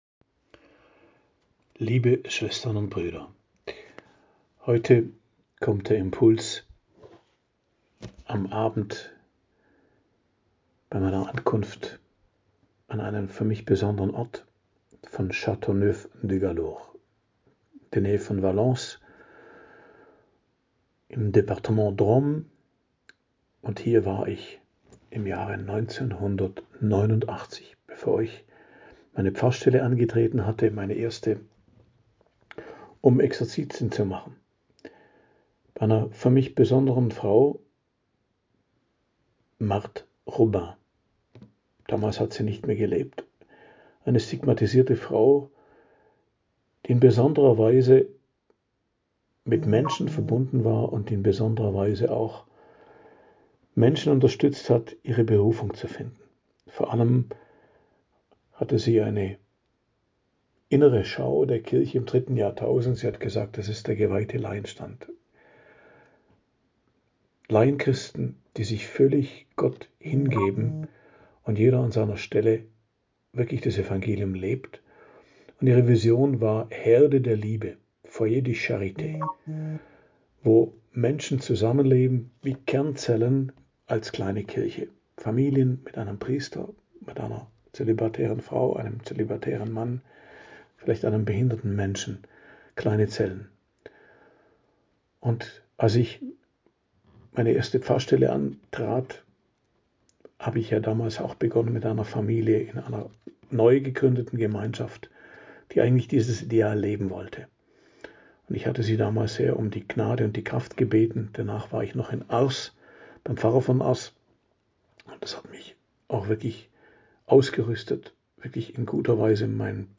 Predigt am Dienstag der 19. Woche i.J., 12.08.2025